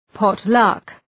Shkrimi fonetik {,pɒt’lʌk}